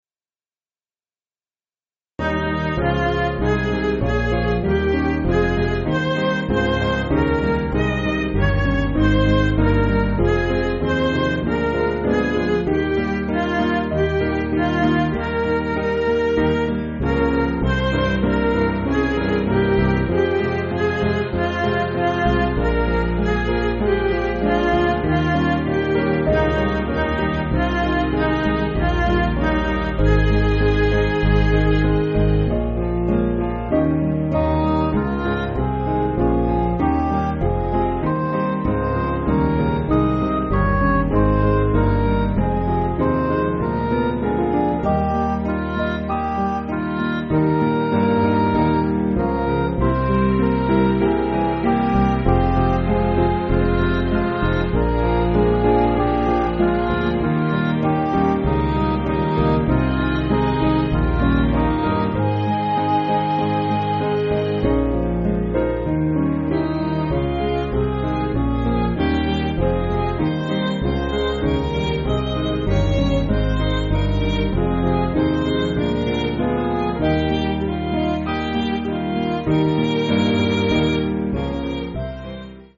Piano & Instrumental
(CM)   3/Ab